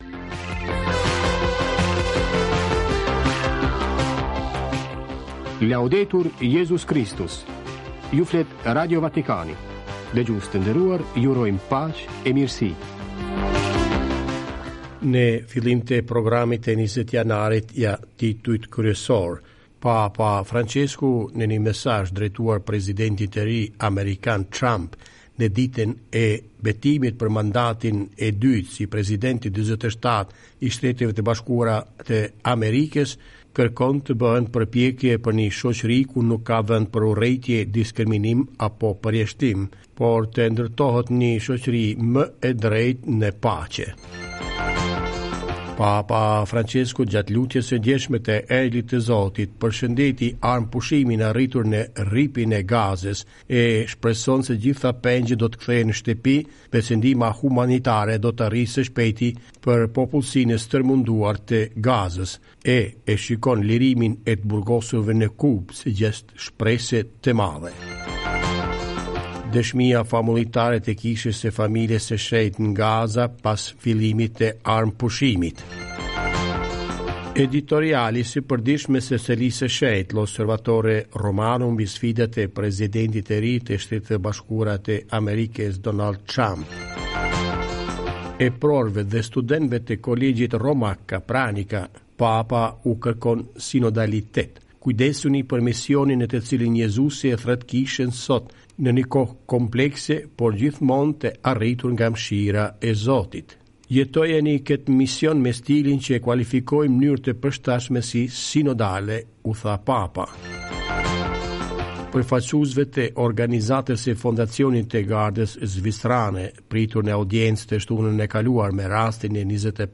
Veprimtaria e Papës dhe e Selisë së Shenjtë, intervista, lajme e reflektime mbi fenë e krishterë, doktrinën shoqërore të Kishës Katolike; puna e Kishës në trevat shqiptare në favor të popullit e të besimtarëve.